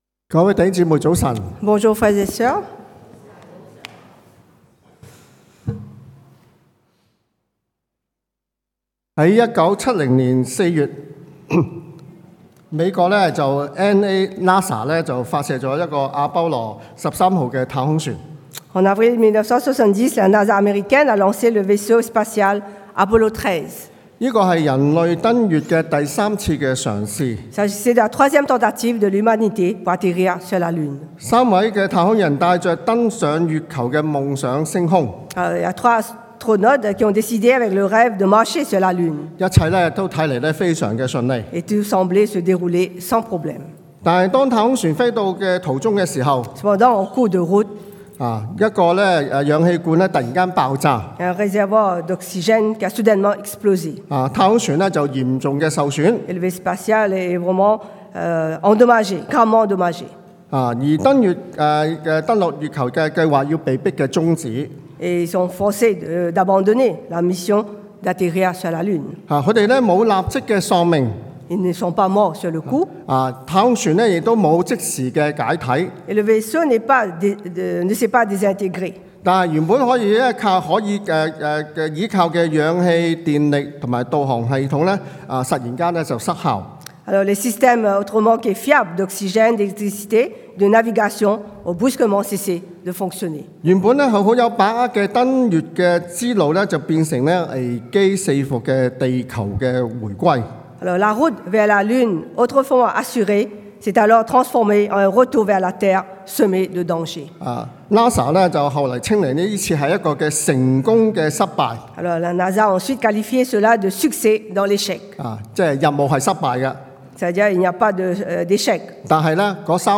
Passage: Josué 约书亚记 7 : 1-12 Type De Service: Predication du dimanche « Grâce offerte